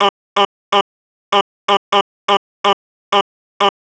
cch_vocal_loop_up_125.wav